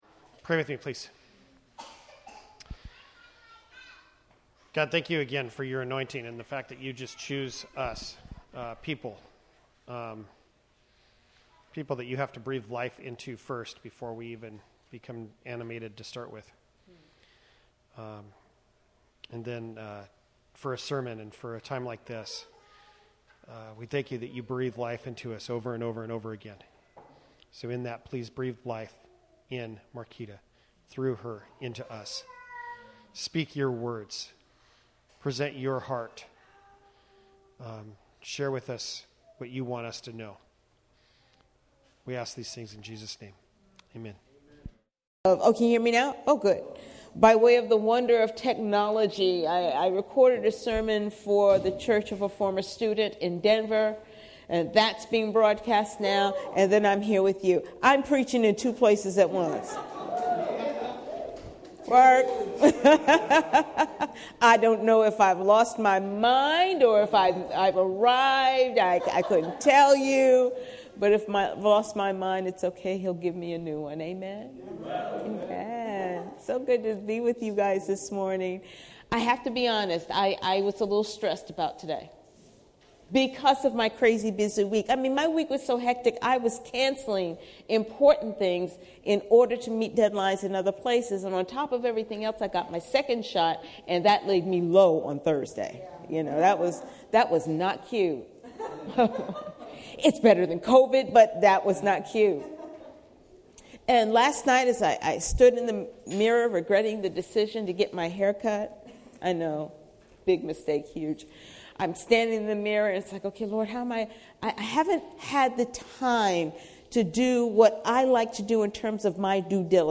Acts 2:1-13 Service Type: Sunday Morning Related « Corporate Identity The Spirit is Here!